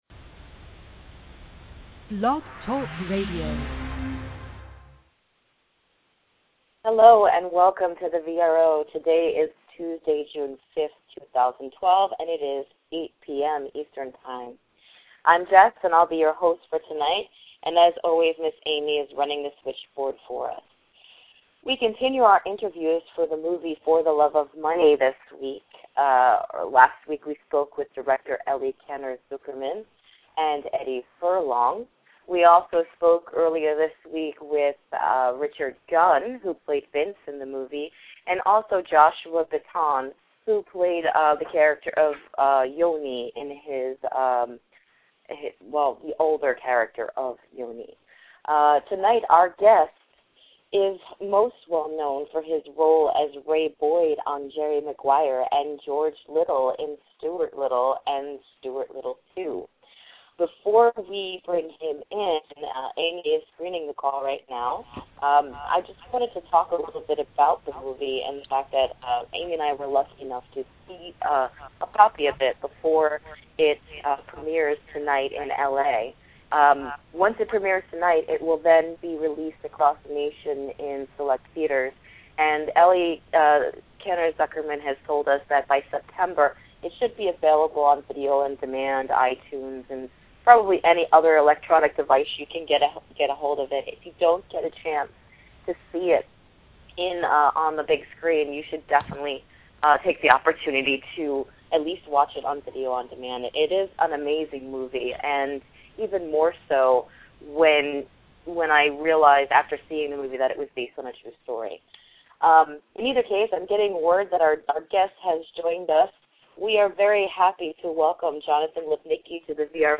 Jonathan Lipnicki Interview